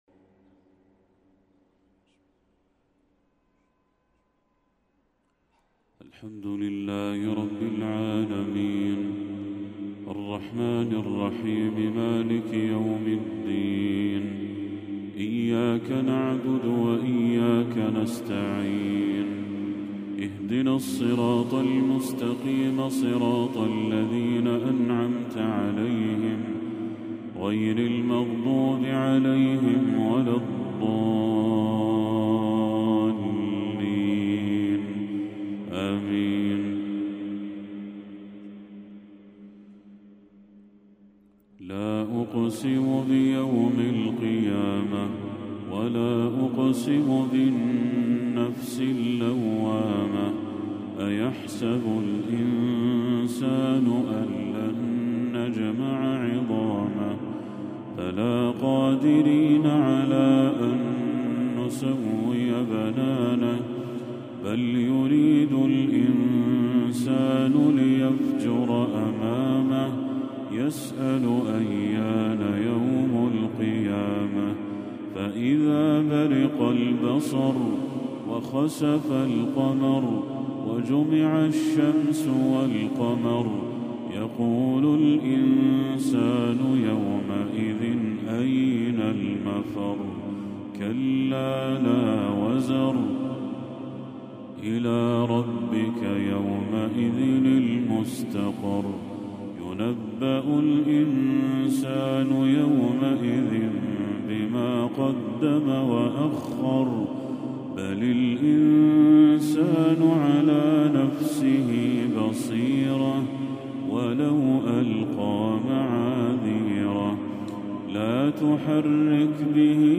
تلاوة مؤثرة لسورة القيامة للشيخ بدر التركي | عشاء 22 ربيع الأول 1446 > 1446هـ > تلاوات الشيخ بدر التركي > المزيد - تلاوات الحرمين